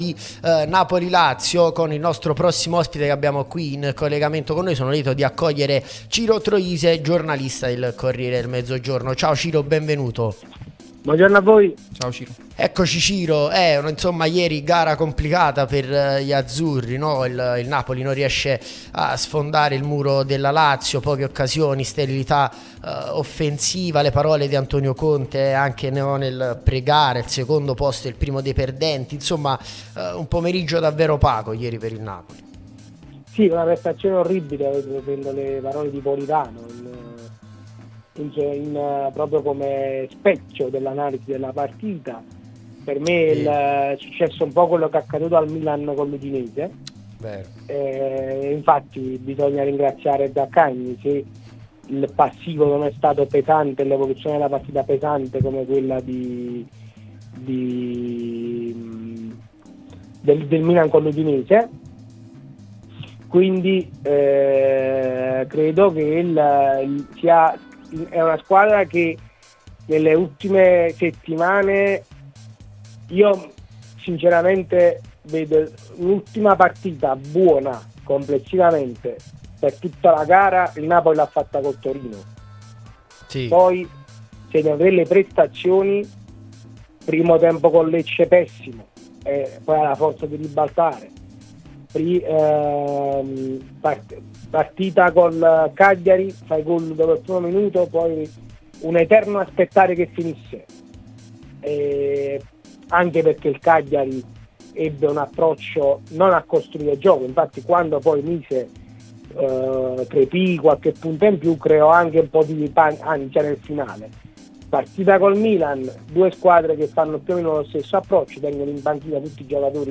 è intervenuto su Radio Tutto Napoli